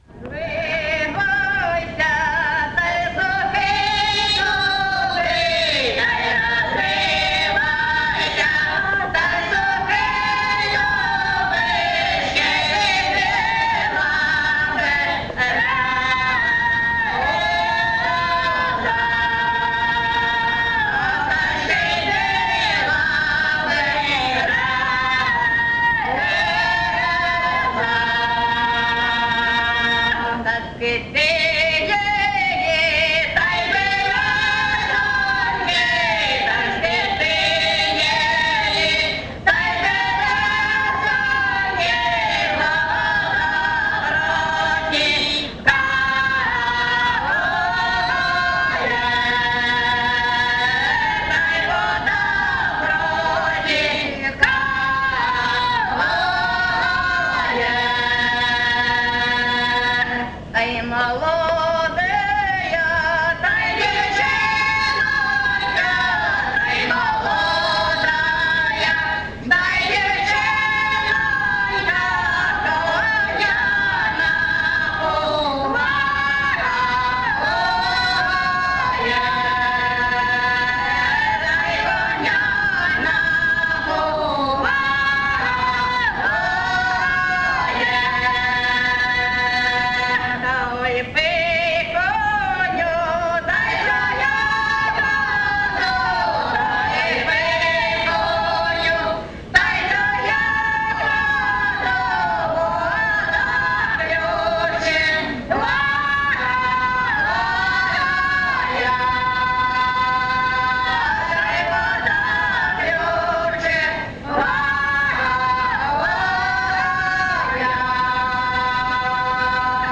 ЖанрПісні з особистого та родинного життя
Місце записус. Очеретове, Валківський район, Харківська обл., Україна, Слобожанщина